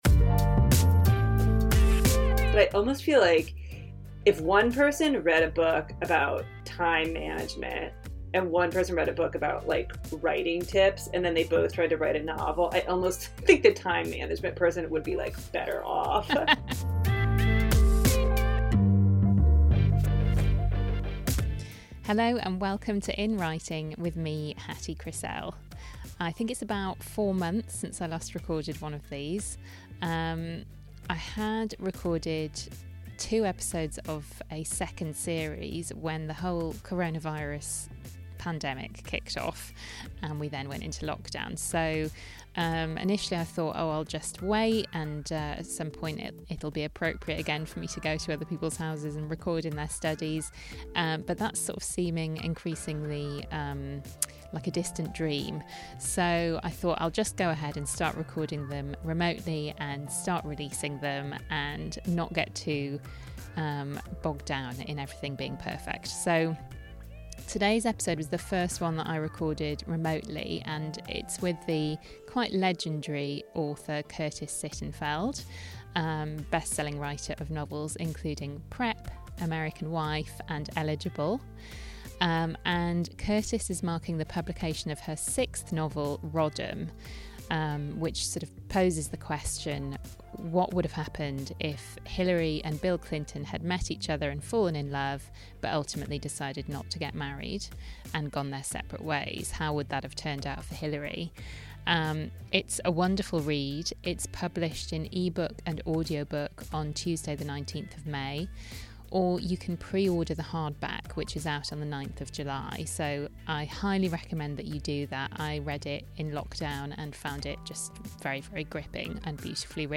This week, from my duvet fort in London, I speak to Curtis Sittenfeld in her small, distraction-free study (which she likens to Harry Potter’s under-stair bedroom) in Minneapolis, Minnesota.